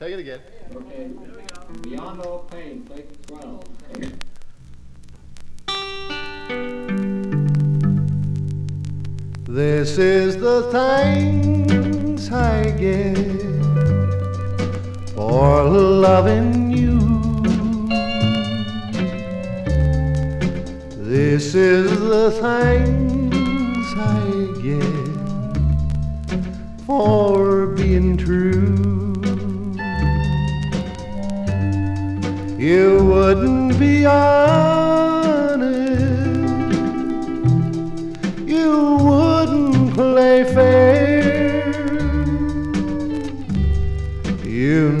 Country, Hillbilly　UK　12inchレコード　33rpm　Mono